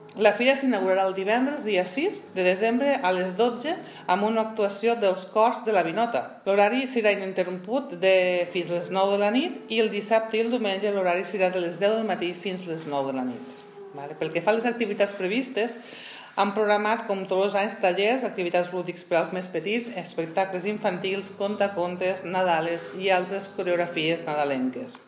La regidora de Comerç, Sònia Rupérez, ha destacat que la programació d’activitats de Nadal a Tortosa s’amplia amb altres esdeveniments culturals, com el Parc de Nadal i diverses activitats organitzades per la Federació de Comerç Tortosa Més i les associacions de veïns de diferents barris.